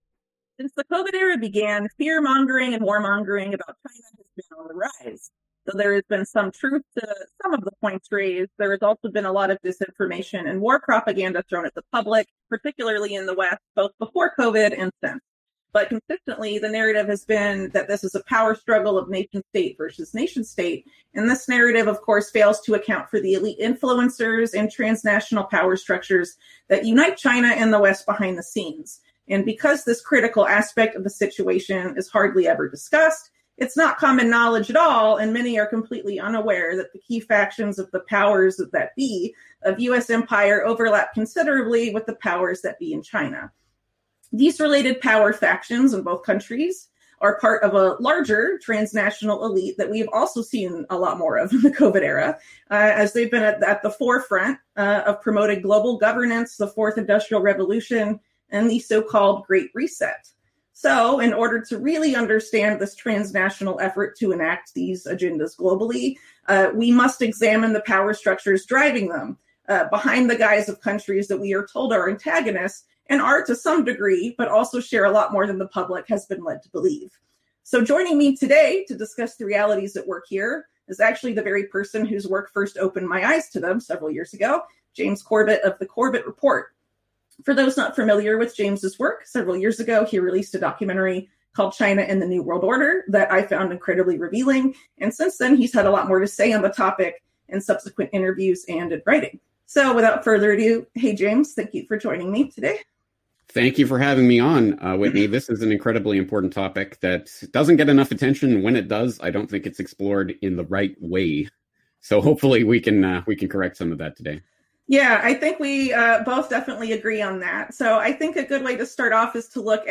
This is the audio only version of the video interview available here.